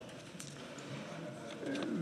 20.9.2017Wortmeldung
Session des Kantonsrates vom 18. bis 20. September 2017